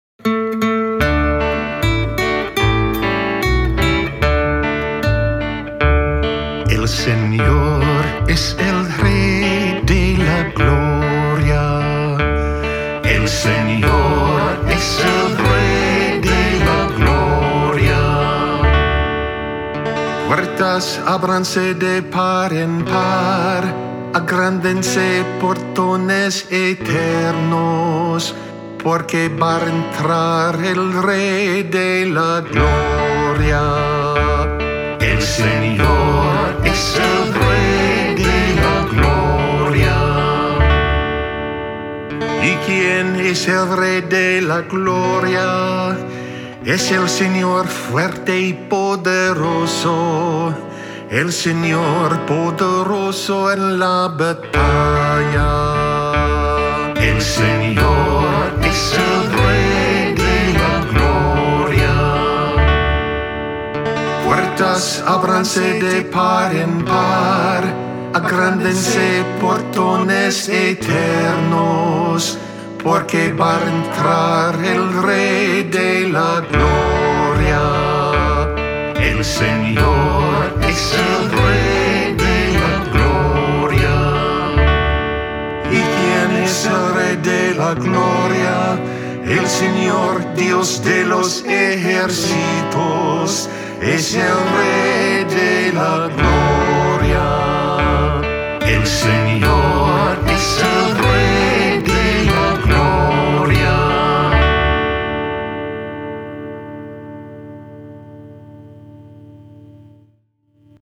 Audio demo (.mp3) and sheet music (.pdf) are available.